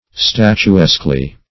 Search Result for " statuesquely" : The Collaborative International Dictionary of English v.0.48: Statuesquely \Stat`u*esque"ly\, adv. In a statuesque manner; in a way suggestive of a statue; like a statue.